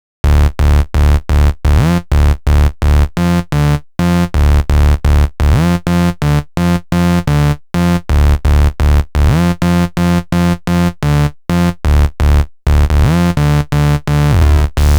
Session 11 - Bass 01.wav